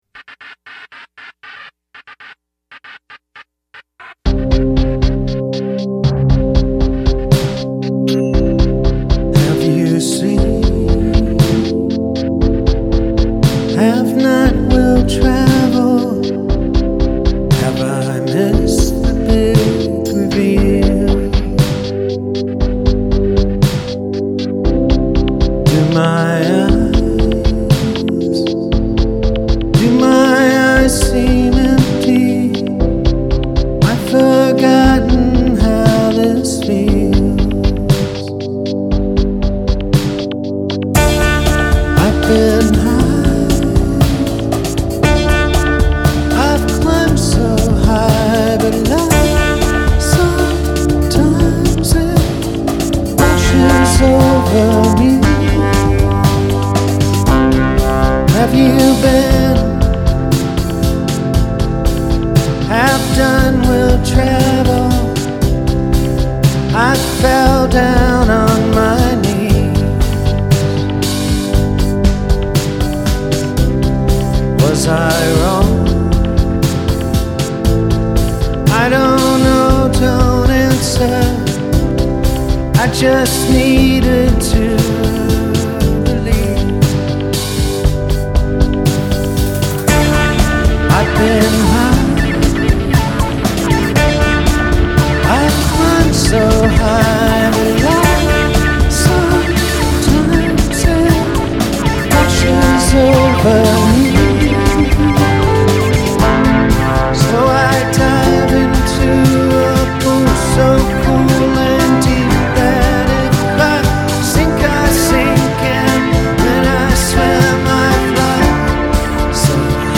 It’s a gentle ballad with electronica flourishes
It’s a lovely, melancholy sort of song.